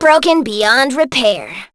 jess_kill_03.wav